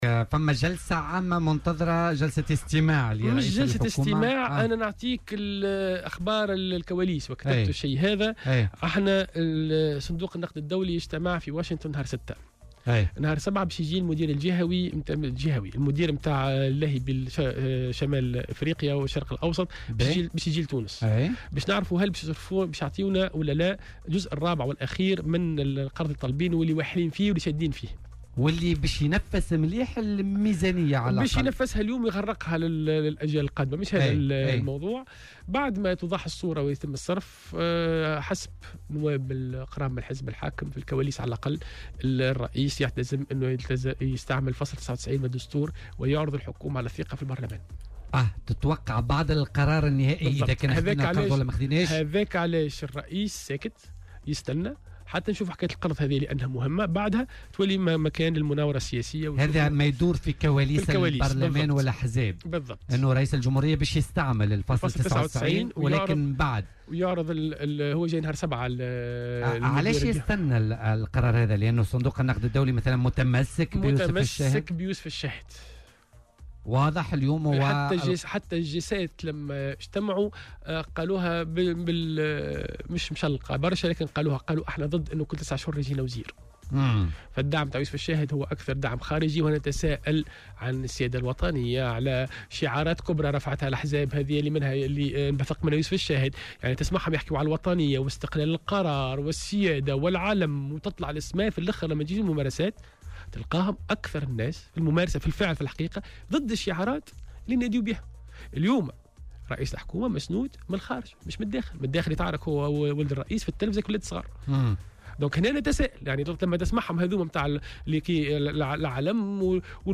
وأوضح العياري، ضيف برنامج "بوليتيكا" اليوم الخميس أن رئيس الجمهورية في انتظار المصادقة على القسط الرابع من صندوق النقد الدولي، لأن الصندوق متمسك بيوسف الشاهد، وفق تعبيره.